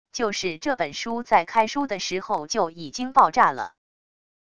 就是这本书在开书的时候就已经爆炸了wav音频生成系统WAV Audio Player